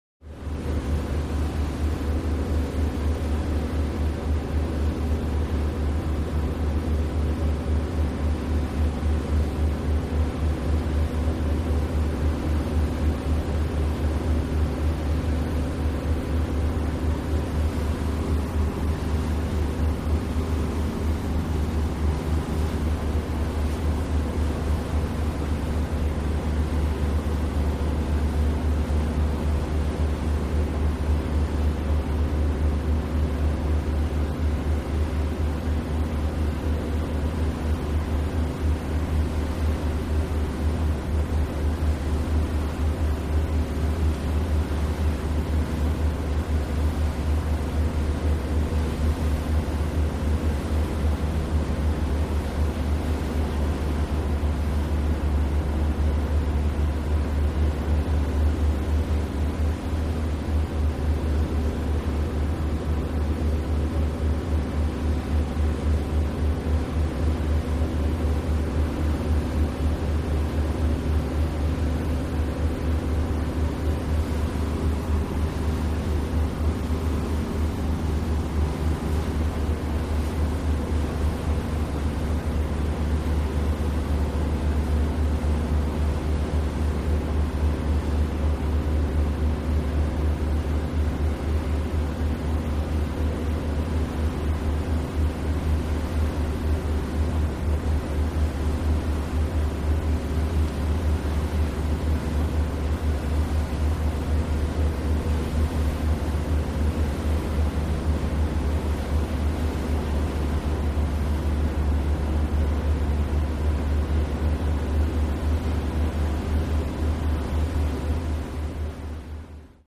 Large Power Yacht Steady.